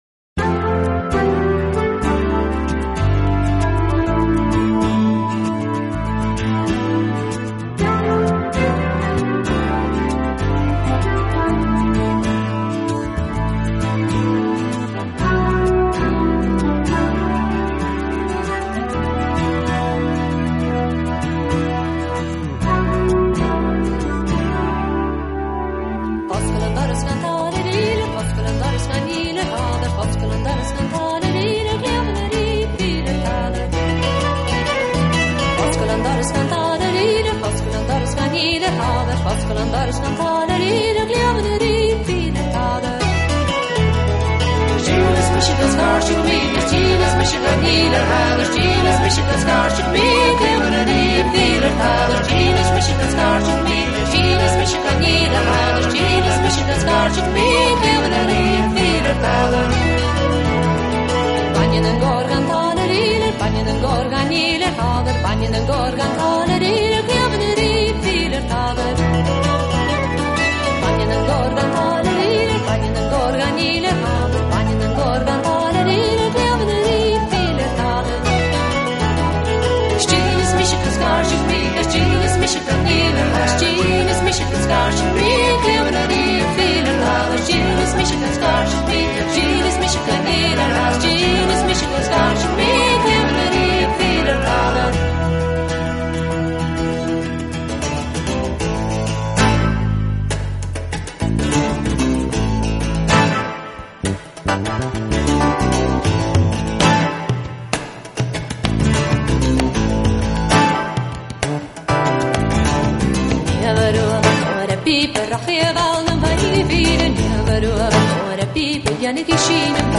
【凯尔特】2004年凯尔特音乐合辑